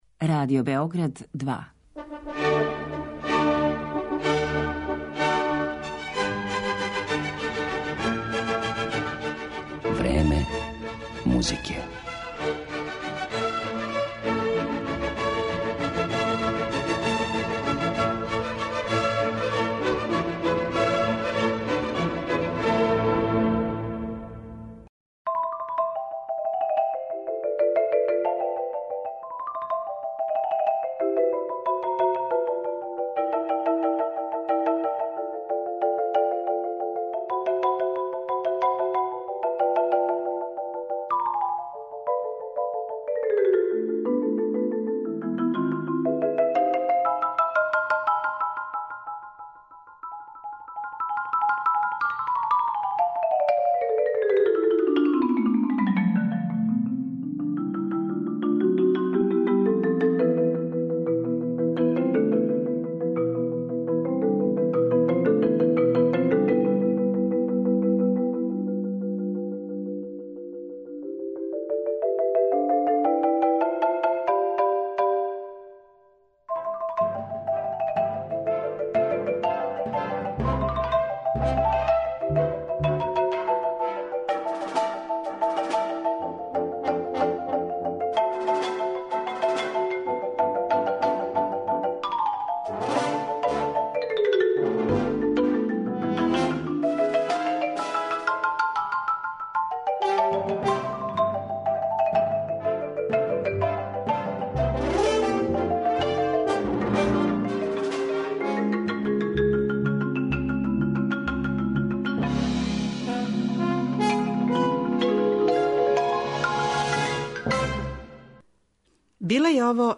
У емисији ћемо емитовати њене снимке, а чућете и транcкрипт интервјуа који је уметница дала нашем програму пре неколико година.